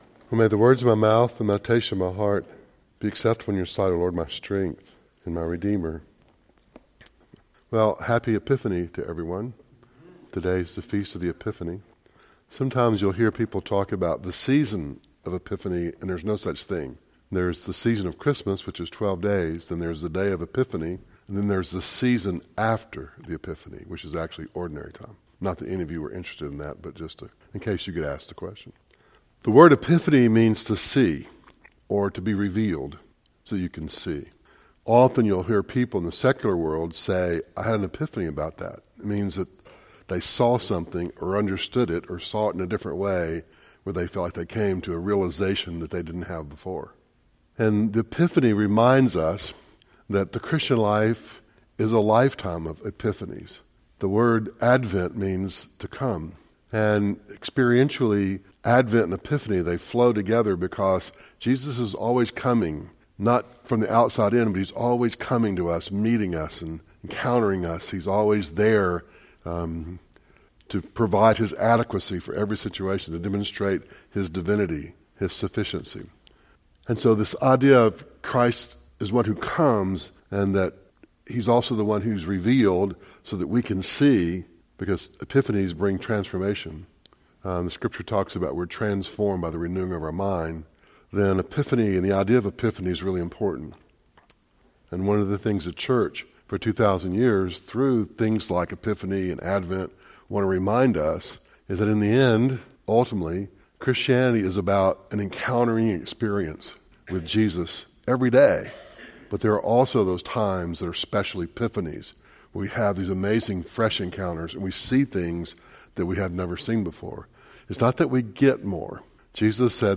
Service Type: Devotional